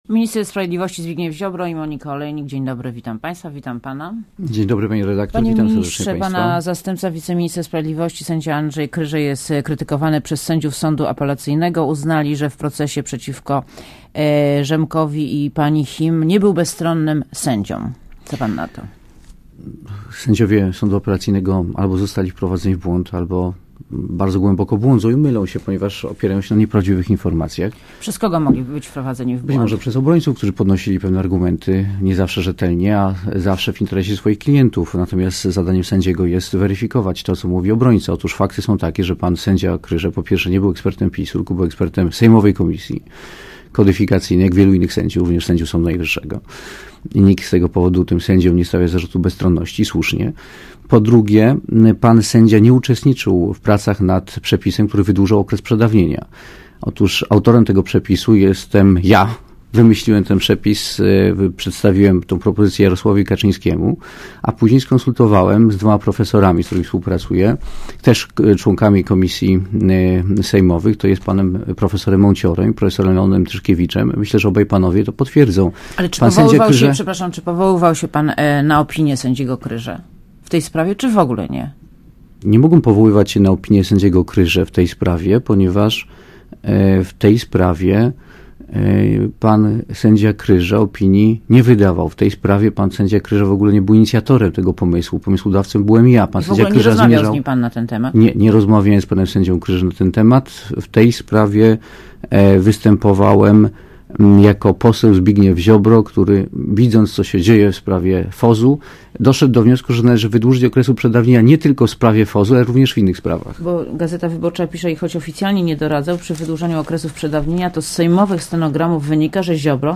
Posłuchaj wywiadu Minister sprawiedliwości Zbigniew Ziobro , Monika Olejnik, witam.